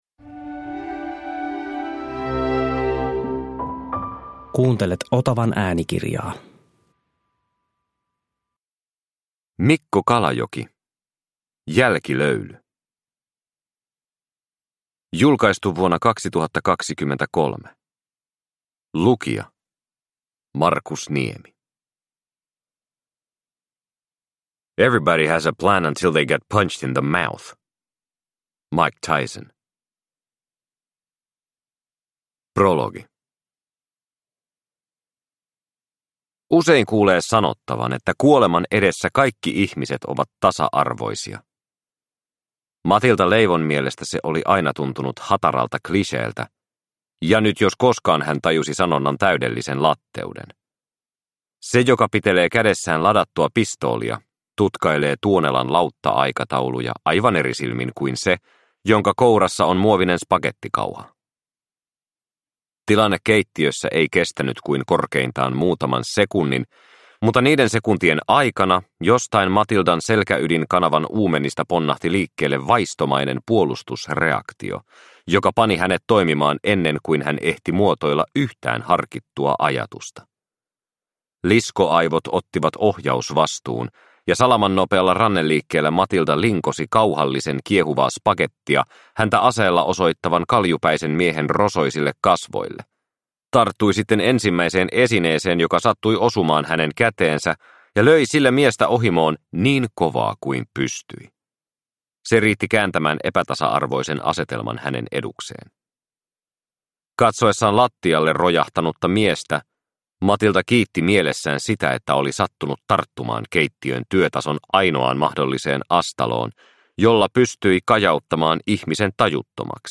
Jälkilöyly – Ljudbok – Laddas ner